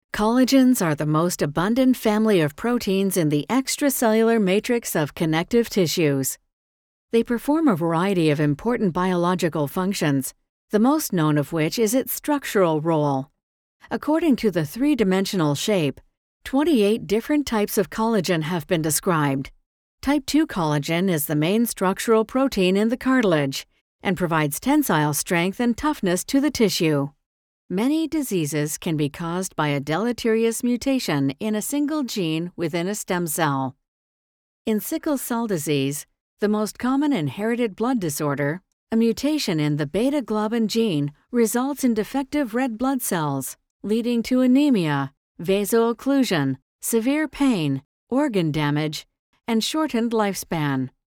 Inglês (canadense)
Narração Médica
Microfone Neumann TLM103, pré-amplificador SSL2, software Goldwave.